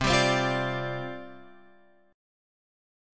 C Suspended 2nd Suspended 4th